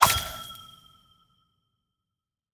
Minecraft Version Minecraft Version latest Latest Release | Latest Snapshot latest / assets / minecraft / sounds / block / trial_spawner / spawn_item3.ogg Compare With Compare With Latest Release | Latest Snapshot
spawn_item3.ogg